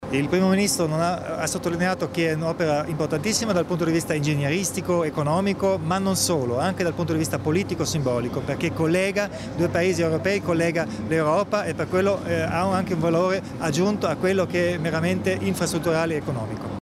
Il Presidente Kompatscher commenta le parole d¿elogio del Premier Renzi sul Tunnel di Base del Brennero